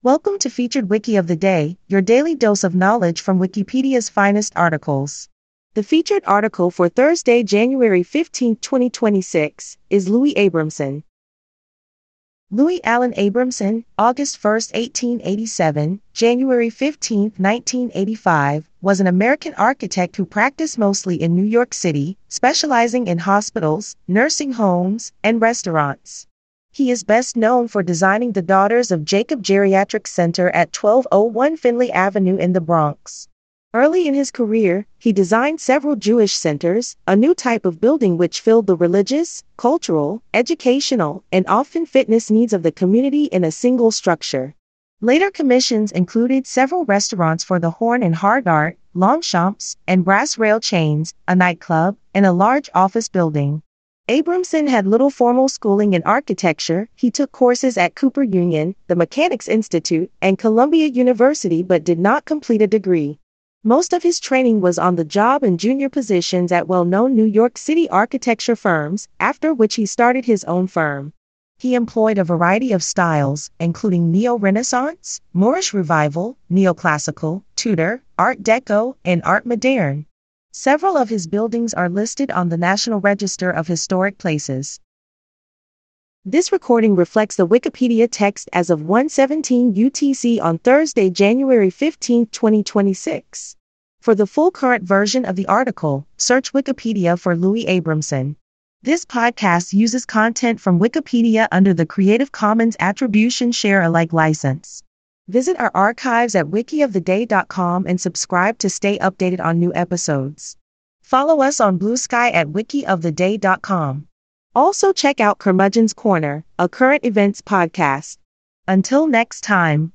dual-drum druid jazz